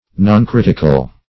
noncritical - definition of noncritical - synonyms, pronunciation, spelling from Free Dictionary